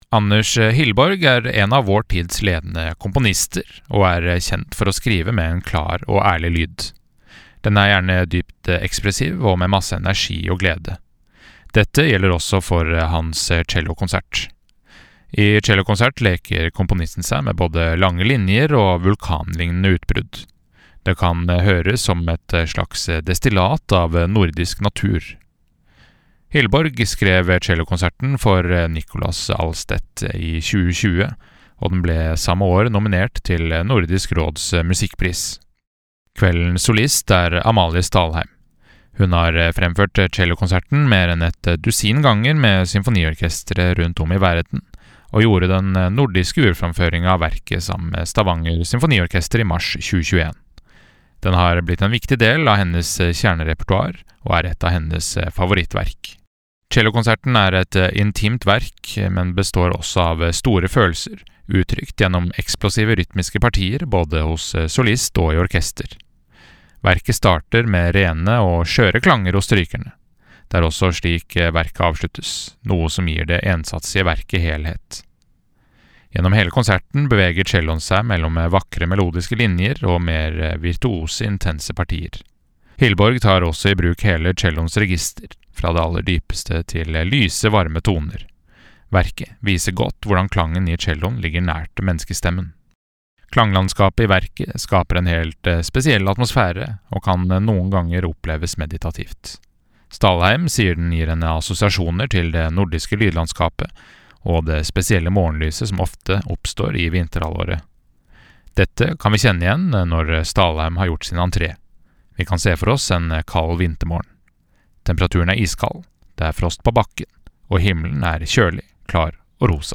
VERKOMTALE: Anders Hillborgs Cellokonsert
VERKOMTALE-Anders-Hillborgs-Cellokonsert.mp3